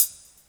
• 90s Hat C# Key 84.wav
Royality free hat tuned to the C# note. Loudest frequency: 10137Hz